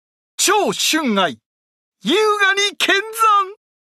サンプルボイス
張郃は美麗に、孟獲は猛々しく。